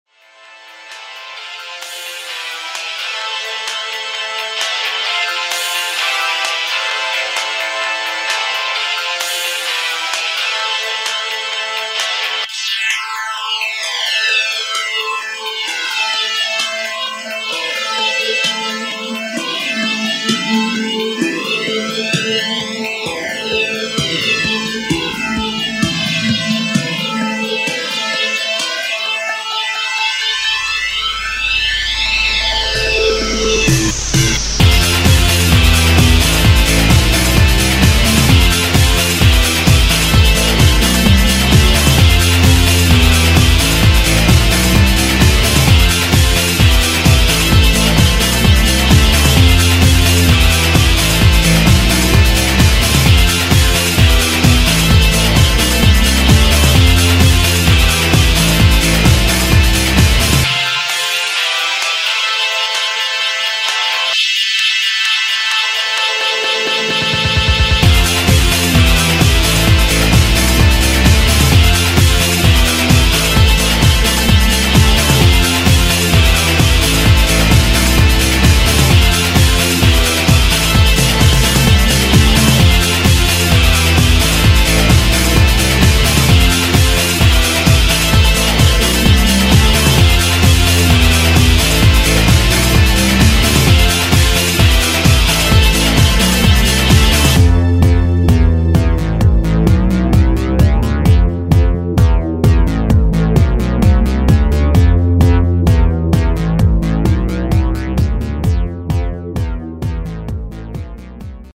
Style: House/Breaks